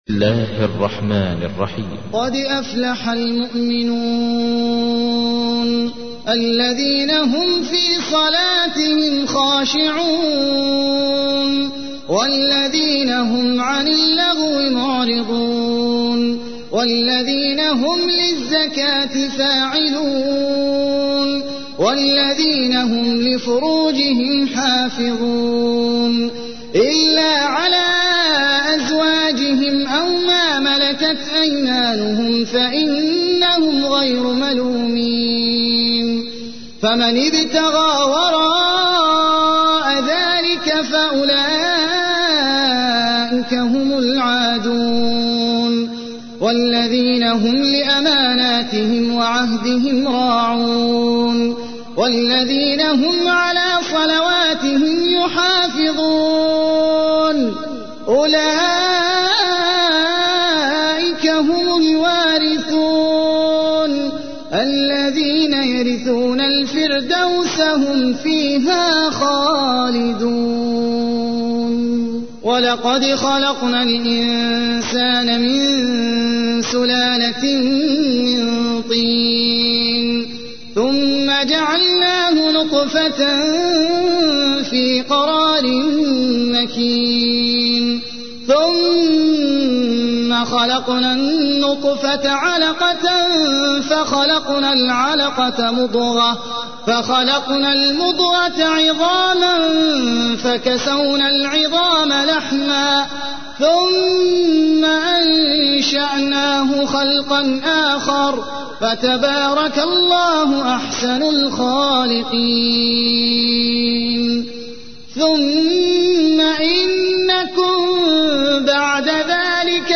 تحميل : 23. سورة المؤمنون / القارئ احمد العجمي / القرآن الكريم / موقع يا حسين